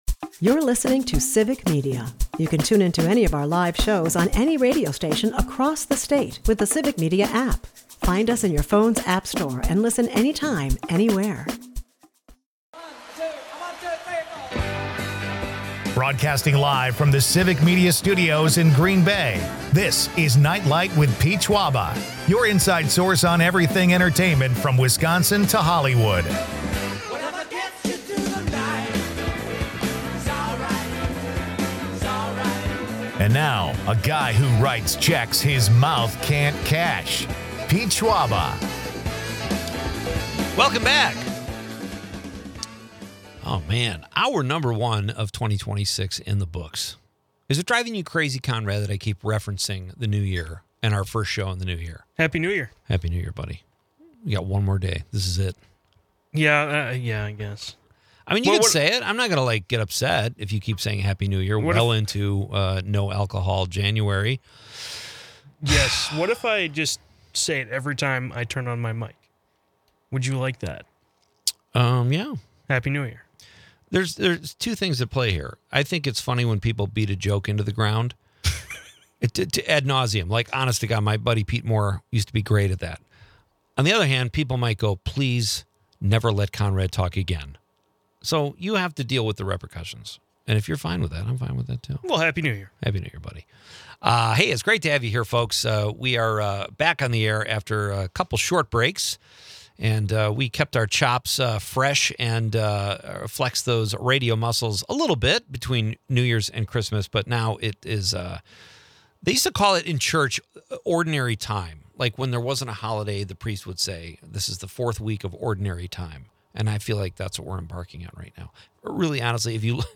The episode spotlights personal New Year resolutions—from gym commitments to giving up high-fructose corn syrup—and the challenges of sticking to them. A heartwarming interview with the Doctors in Recital reveals their upcoming charity event featuring diverse musical talents.